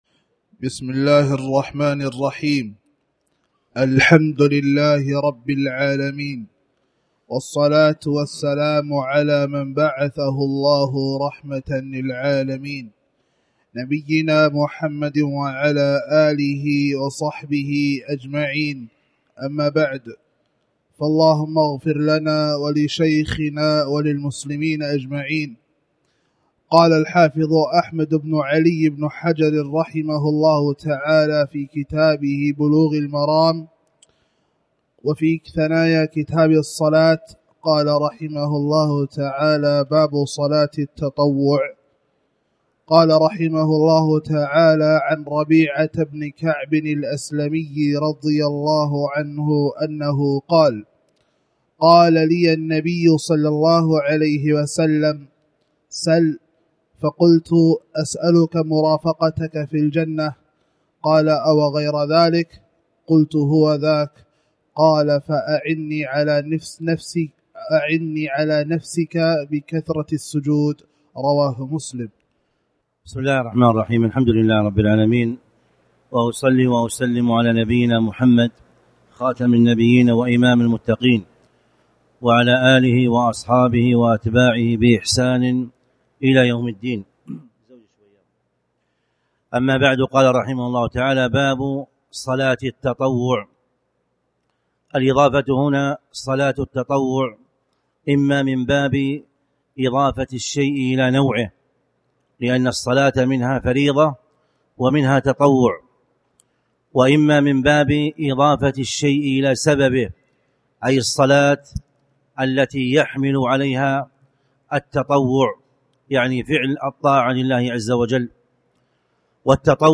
تاريخ النشر ٢٩ محرم ١٤٣٩ هـ المكان: المسجد الحرام الشيخ